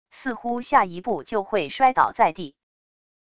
似乎下一步就会摔倒在地wav音频生成系统WAV Audio Player